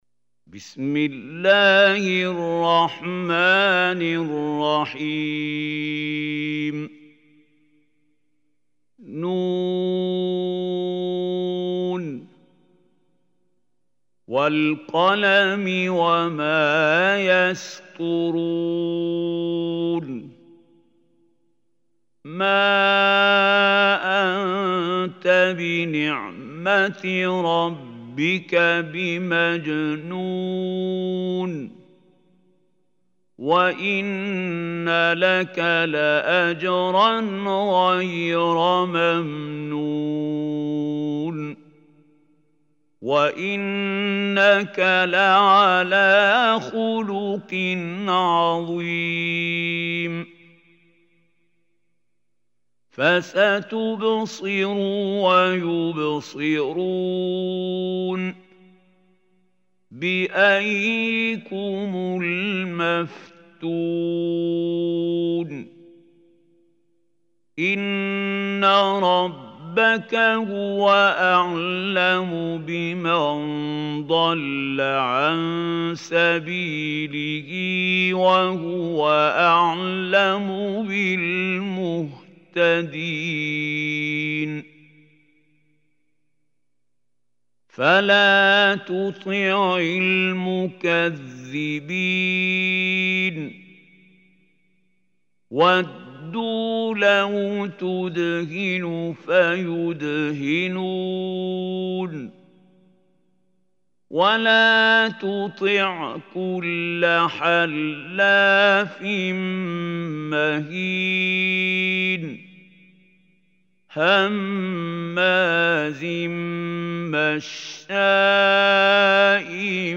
Surah Qalam MP3 Recitation by Mahmoud Khalil
Surah Qalam is 68 surah of Holy Quran. Listen or play online mp3 tilawat/ recitation in Arabic in the beautiful voice of Sheikh Mahmoud Khalil Hussary.